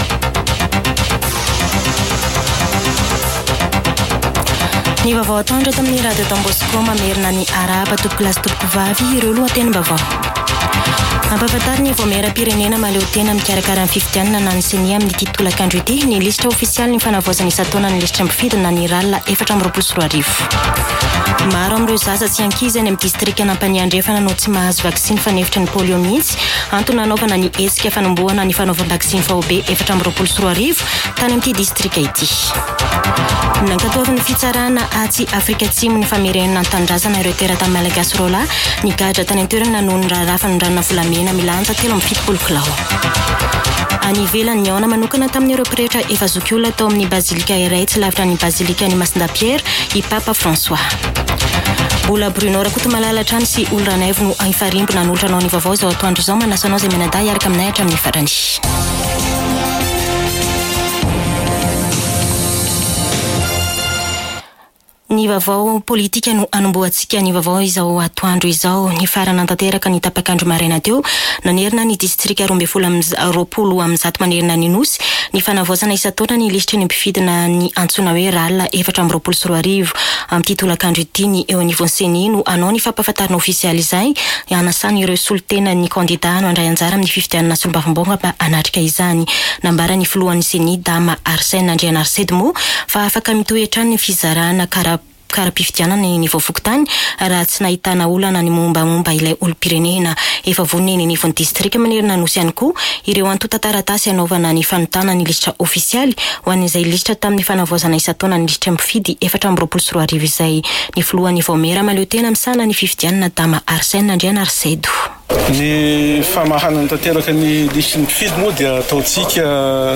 [Vaovao antoandro] Alarobia 15 mey 2024